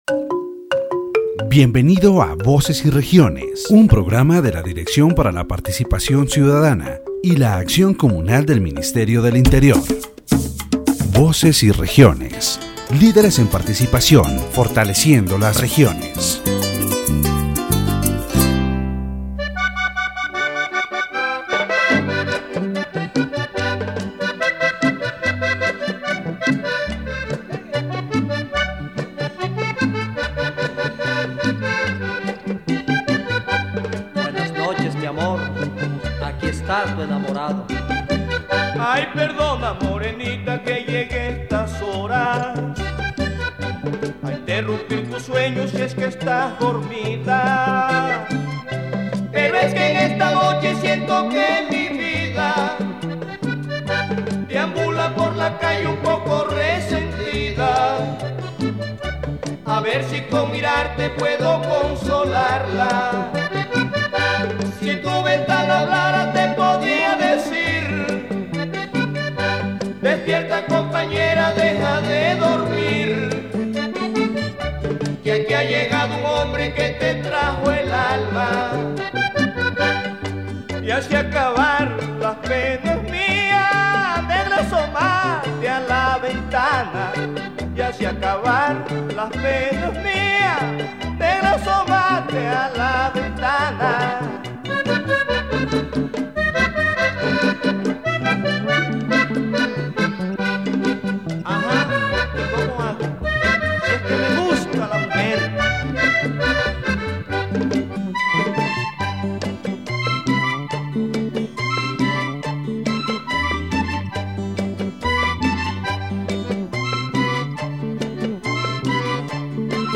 In this episode of Voces y Regiones, broadcast by the Ministry of the Interior and Francisco José de Caldas University through Laud Estereo 90.4 FM, the issue of mining exploitation in La Guajira is discussed.
The interview also highlights social discontent and the struggle of affected communities seeking justice and reparations for the damage suffered.